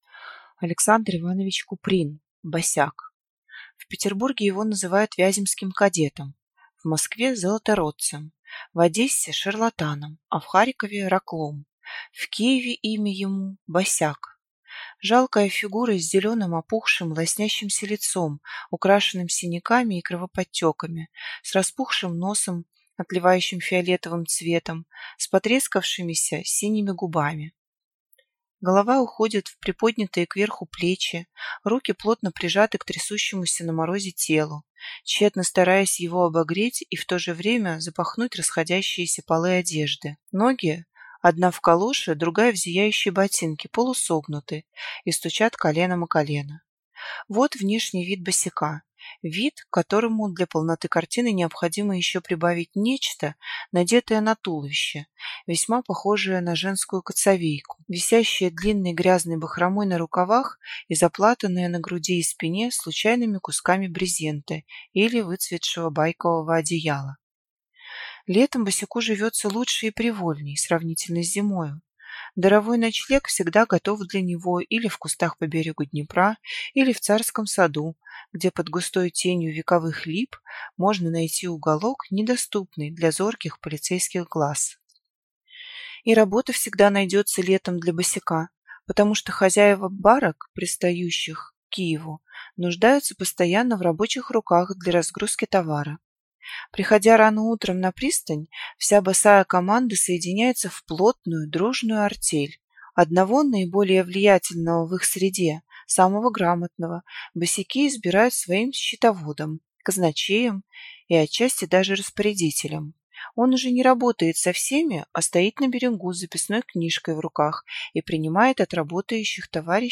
Аудиокнига Босяк | Библиотека аудиокниг